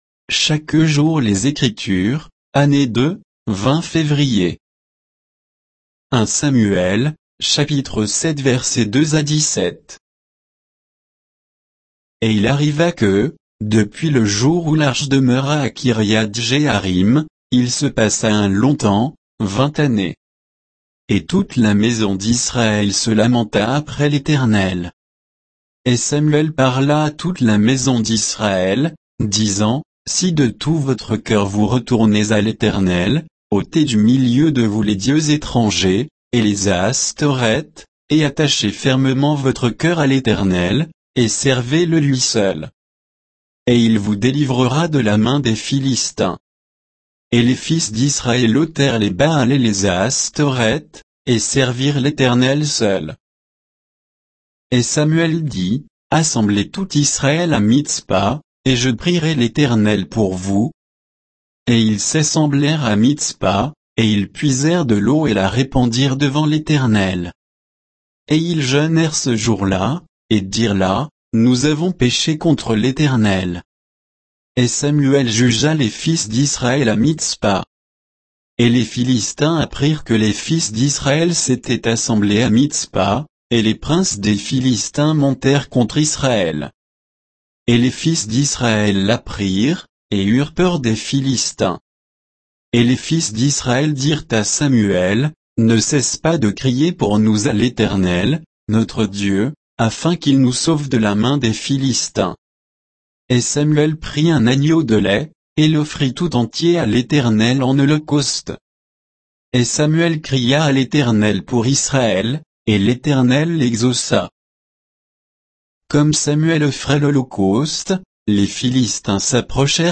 Méditation quoditienne de Chaque jour les Écritures sur 1 Samuel 7